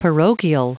Prononciation du mot parochial en anglais (fichier audio)
Prononciation du mot : parochial